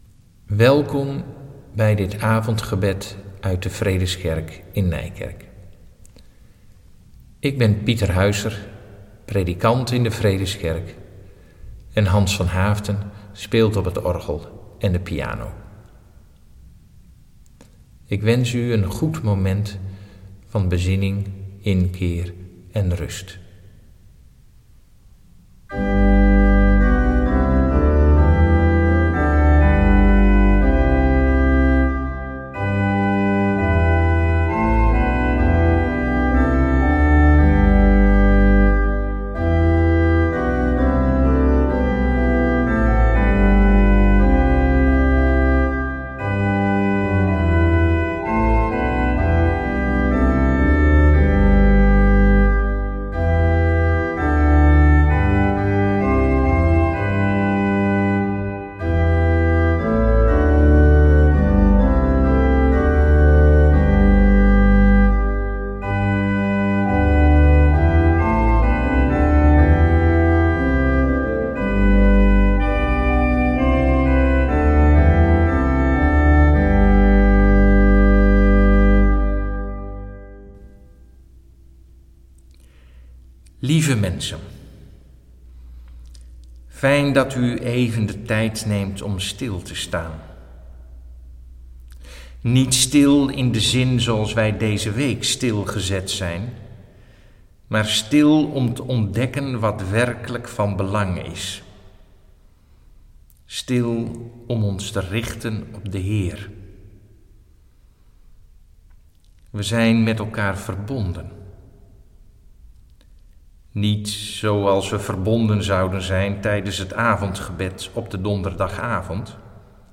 Avondgebed 19/3/2020 online beschikbaar
Zij zullen van tevoren opgenomen worden.
Zelf zal ik de Schriftlezing, een gebed en een meditatie verzorgen.
En dan zullen we afsluiten met het lezen van en luisteren naar Lied 250: 1, 2 en 3.